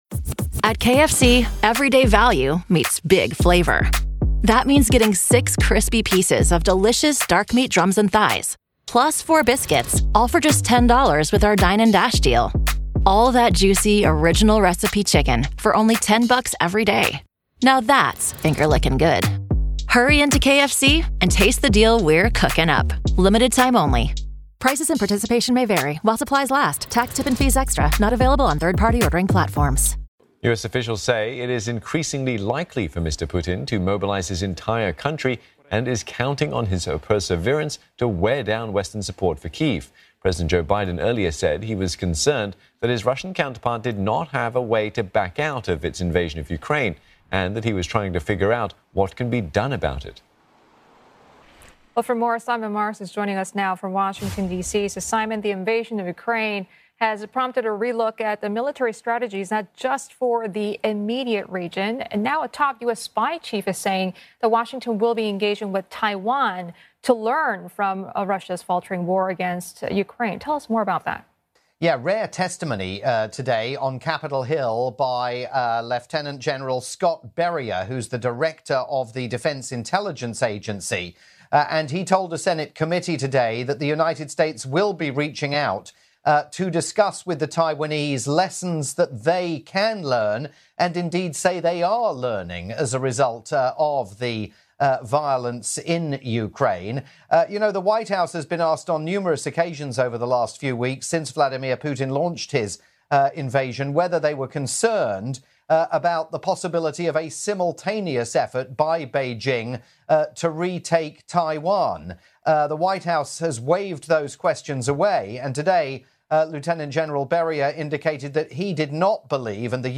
live report for CNA